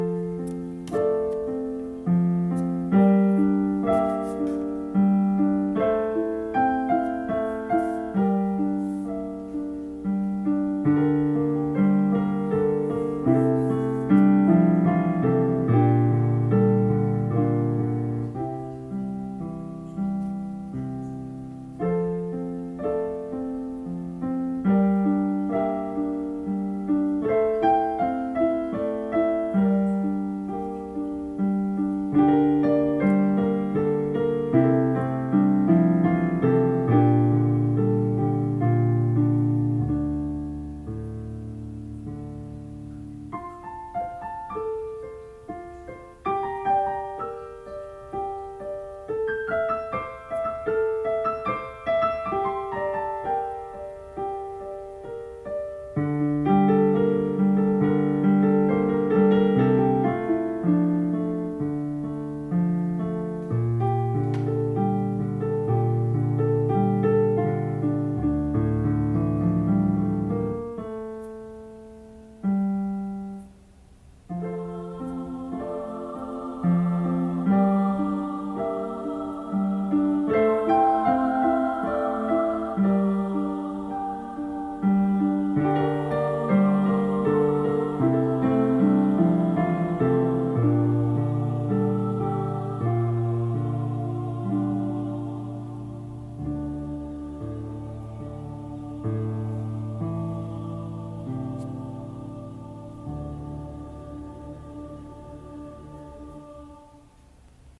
Then as each piece was completed, I would make a recording off of the piano’s playback without using the headphones.
Each was duplicated directly from the piano with a different app and device.
Years later, I added words to it, and, after I got my Yamaha, I added the Choir enhancement to the last section.
Not a good recording, but here goes. And, although I don't know of any way a digital console piano can be "tuned," I do think my keyboard sounds "off."